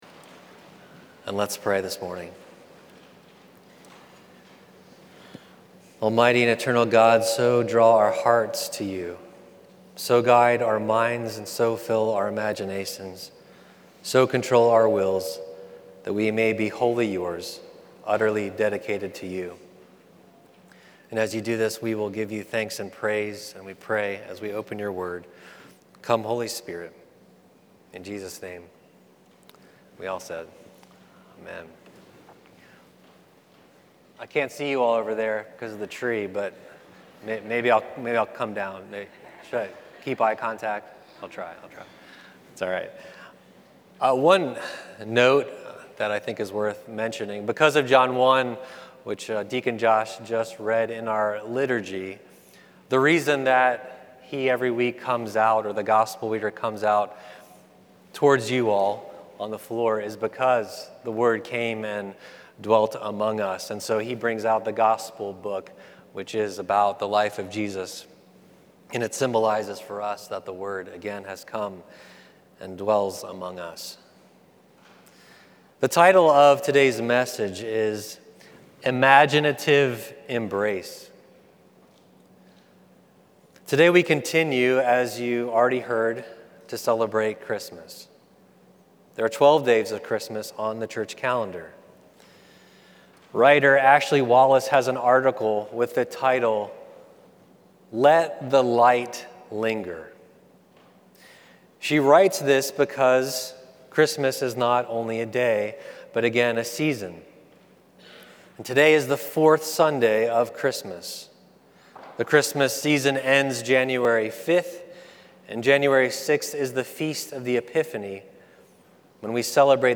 Current Sermon Imaginative Embrace